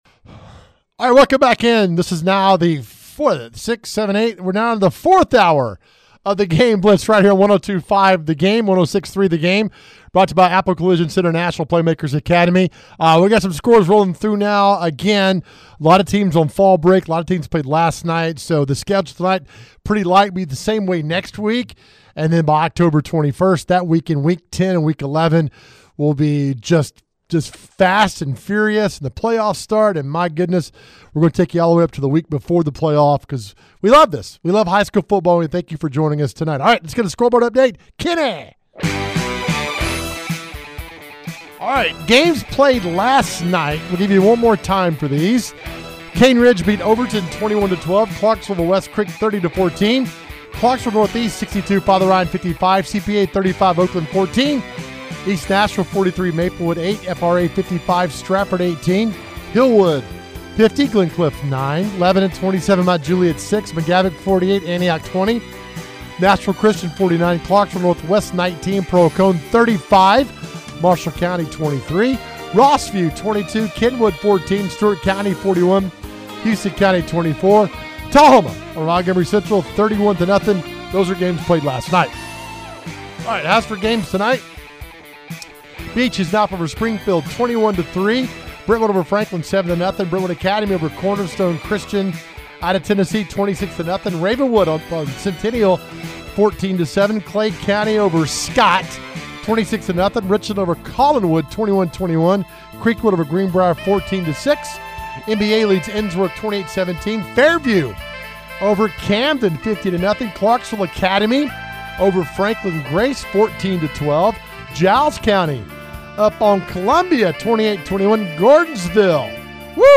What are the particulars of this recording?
They bring you six hours of everything in the world of Middle TN High School Football. We have interviews with coaches and reporters live at the hottest games!